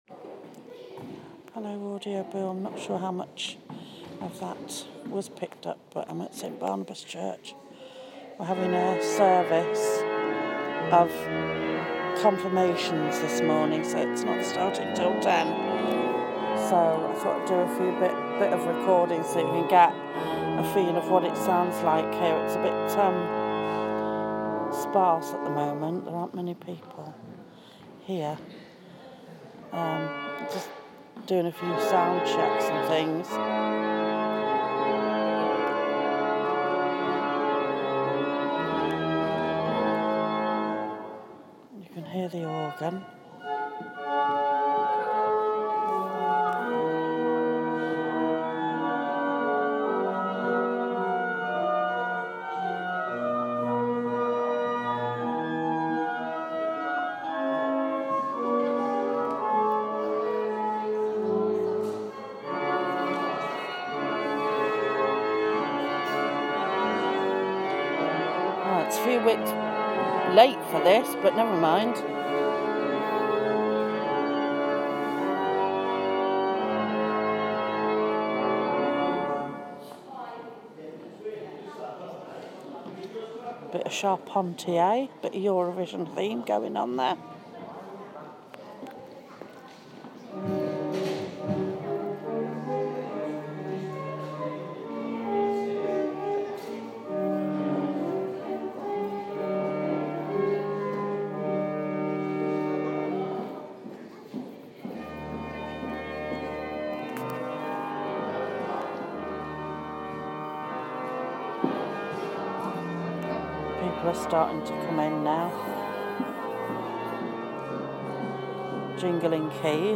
A special Service of Confirmation from my Church led by Arch Bishop Sentimu of York